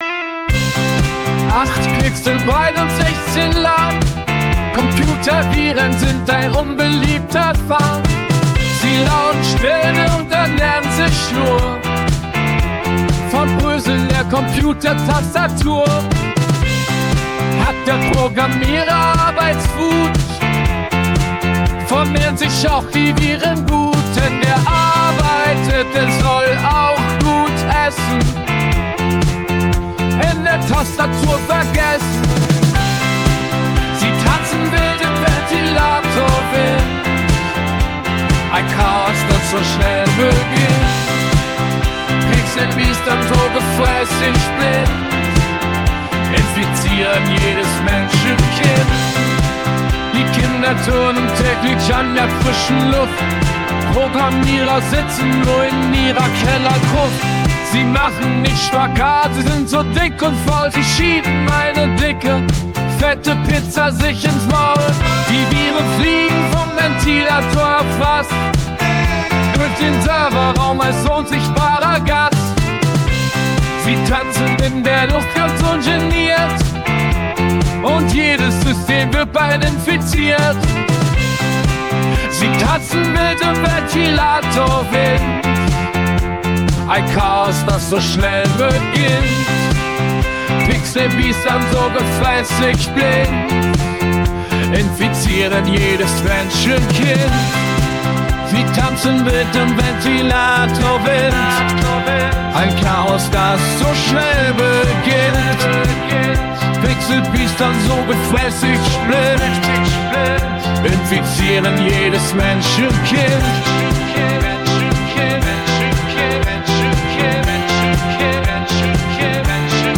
Projekt: KI-unterstütztes Punkalbum